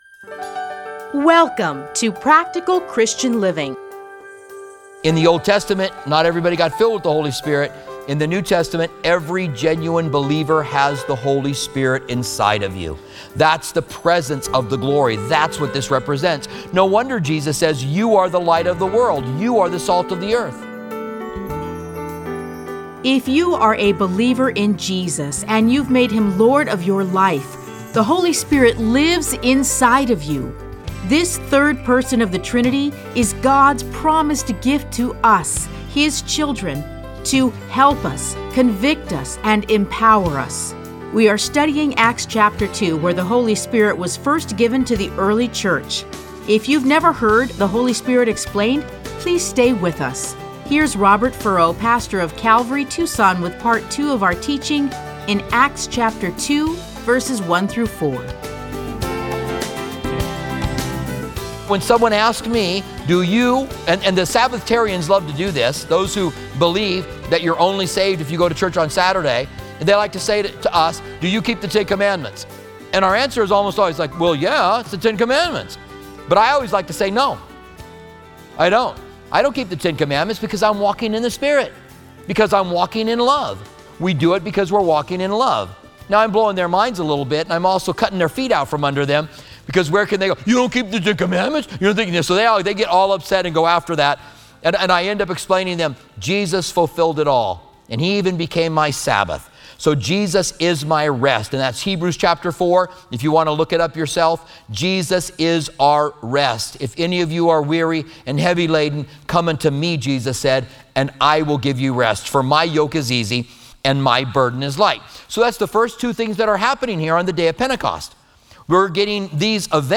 Listen to a teaching from Acts 2:1-4.